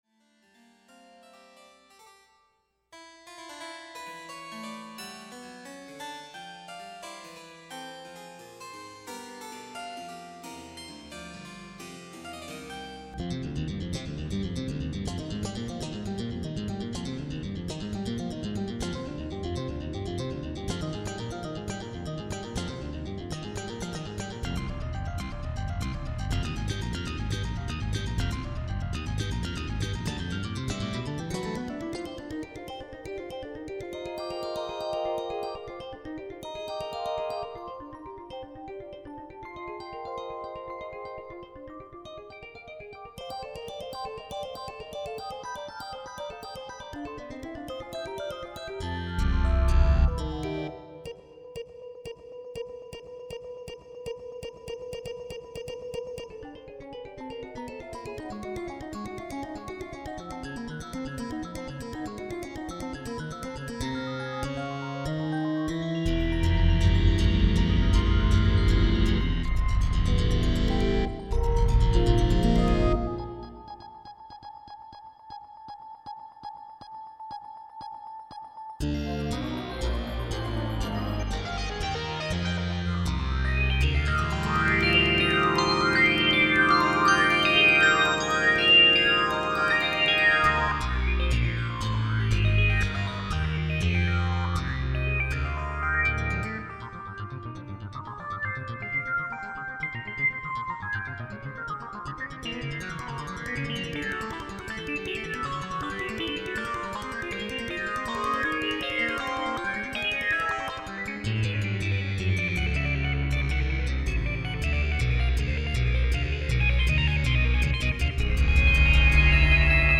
1. 100 bpm [piano]